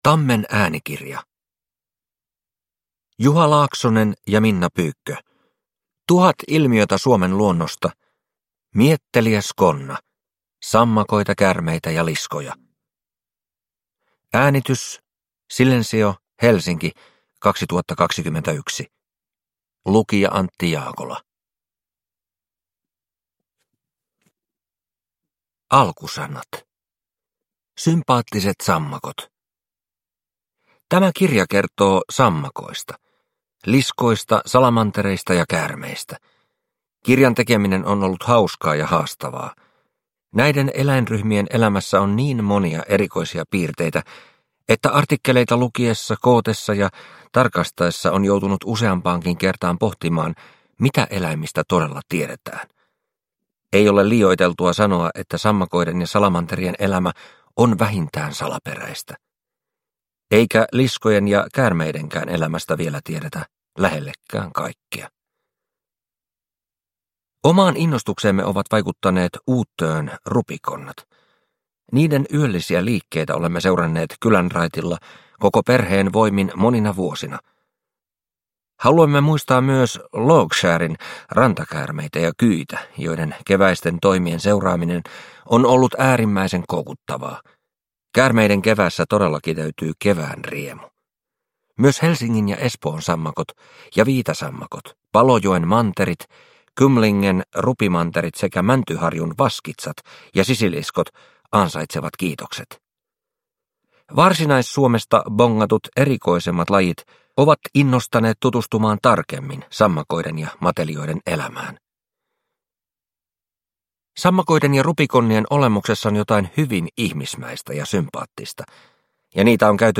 Mietteliäs konna – Ljudbok – Laddas ner